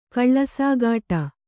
♪ kaḷḷasāgāṭa